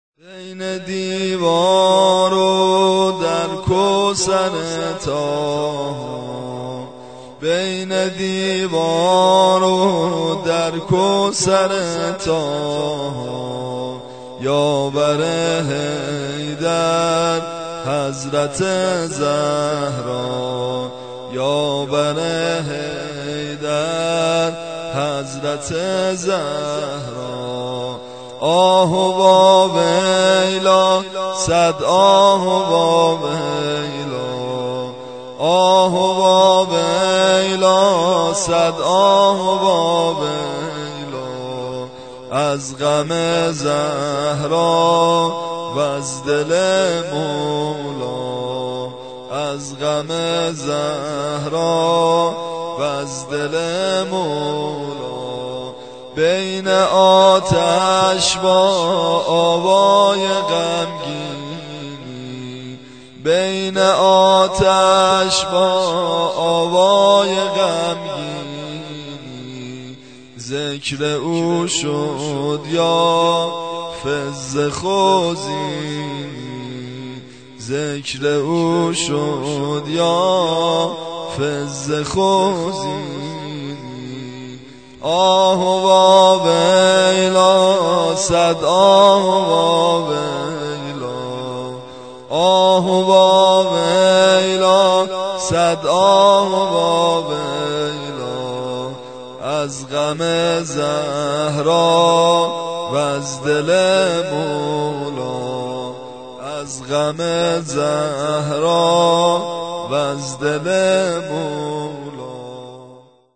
صوت / قابل توجه مداحان جوان / زمینه پیشنهادی (1) + شعر